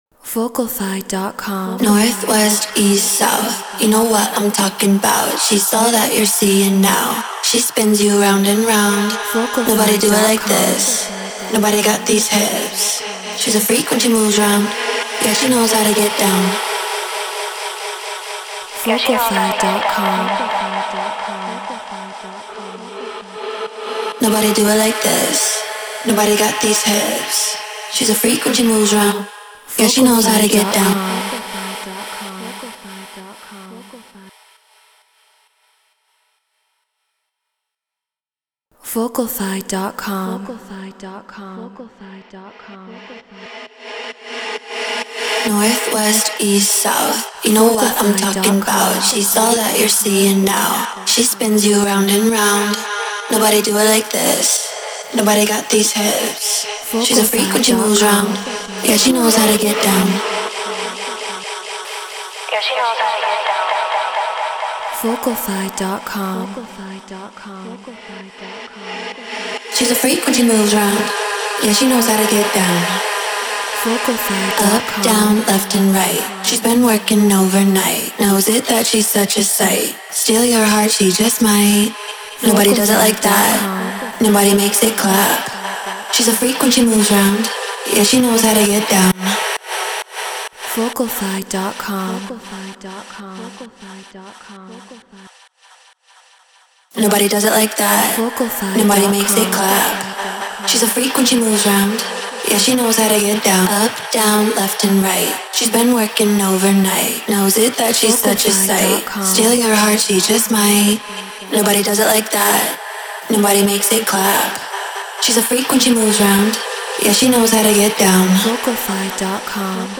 Hard Dance 132 BPM G#
Shure SM7B Scarlett 2i2 4th Gen Ableton Live Treated Room